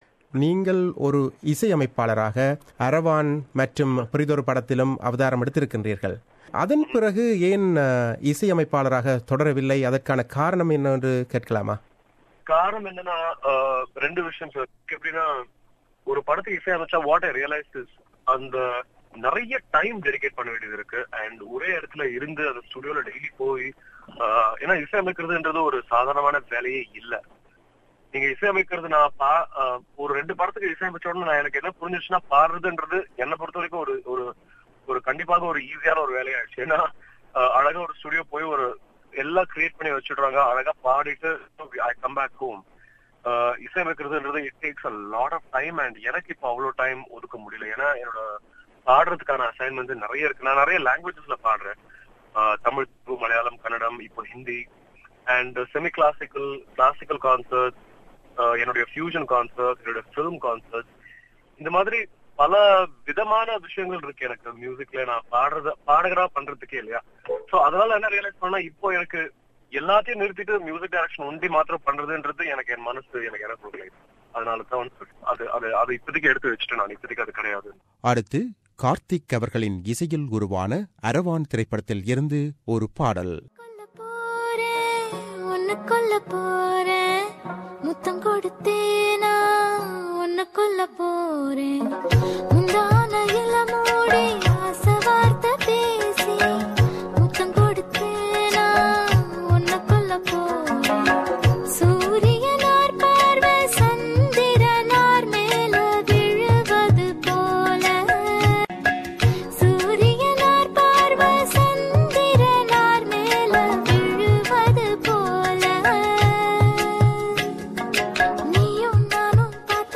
Interview with The Super Star Singer Karthik - Part 2